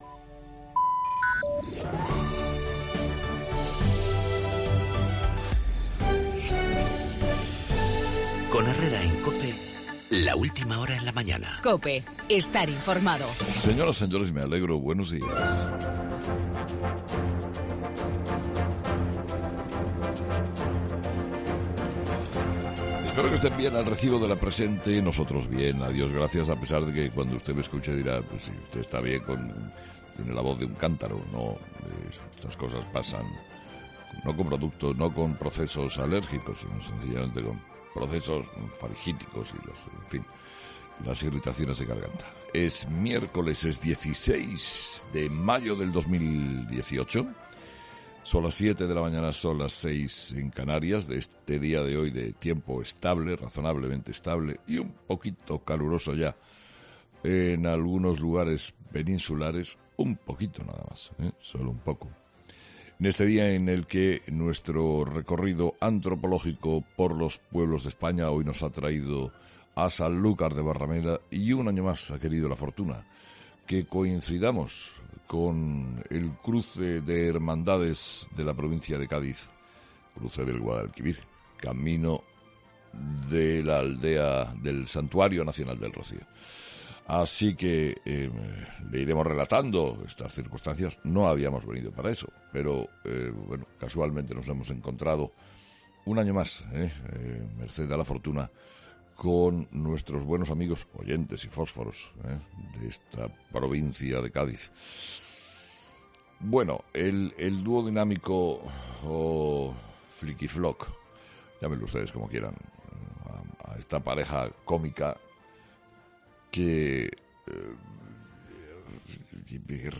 Monólogo de las 8 de Herrera
Escucha ya el monólogo de Carlos Herrera de este miércoles 16 de mayo